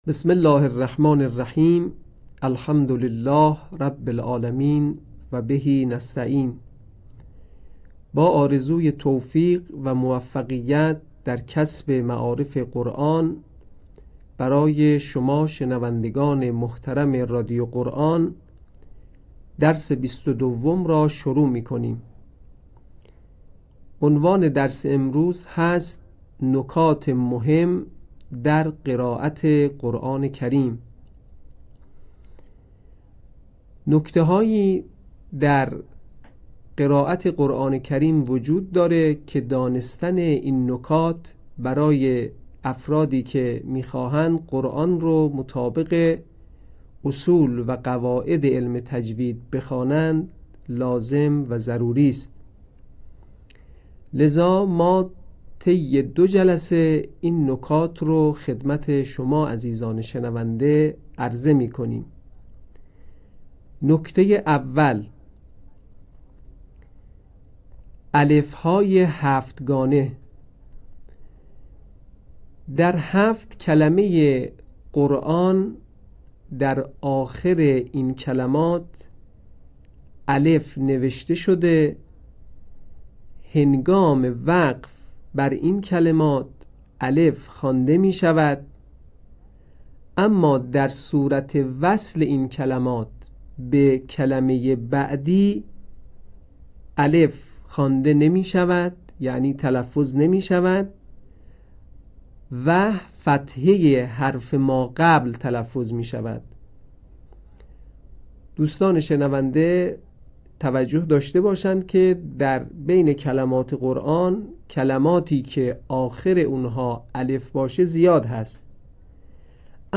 صوت | آموزش الف‌های هفتگانه